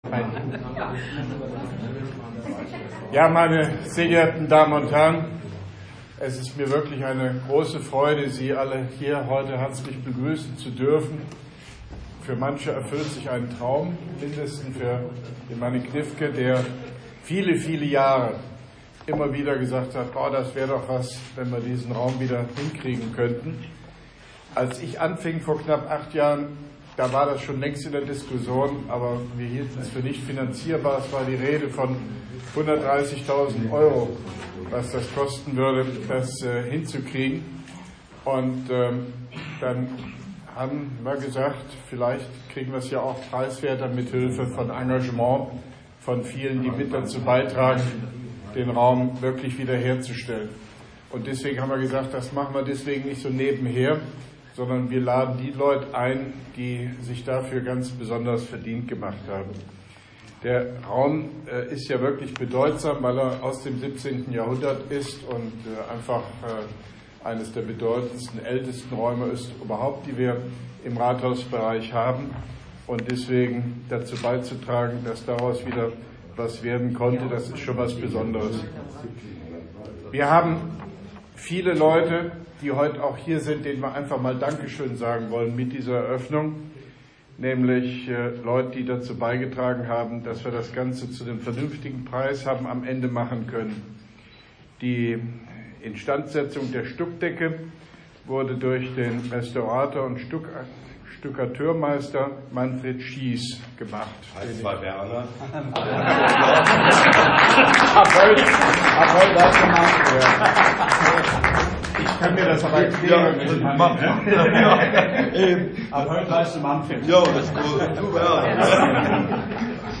Grußwort von OB Hofmann-Göttig zur Einweihung des “Fürstenzimmers” im Koblenzer Rathaus, mit Ratsmitglied Gniffke, Koblenz 18.12.2017